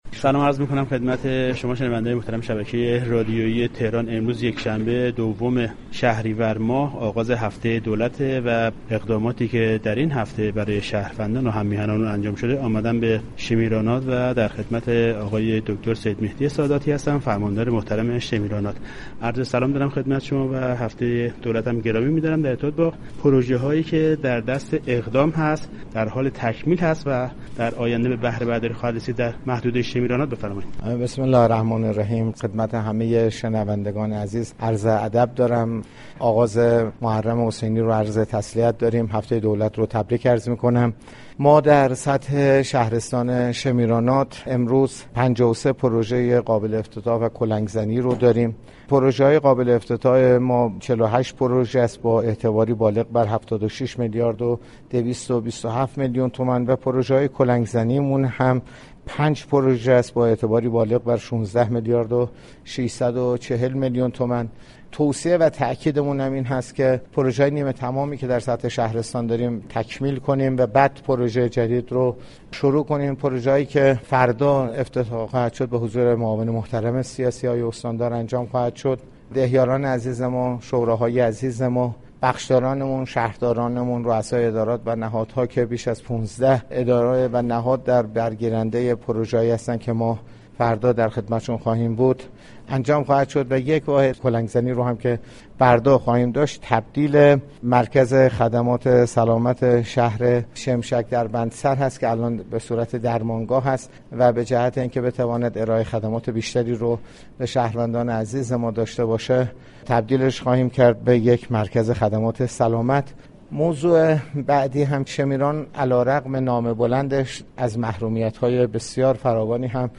دكتر سیدمهدی ساداتی، فرماندار شمیرانات در گفتگوی اختصاصی با رادیو تهران از افتتاح 48 پروژه عمرانی و 5 پروژه كلنگ زنی در شهرستان شمیرانات طی هفته دولت خبر داد.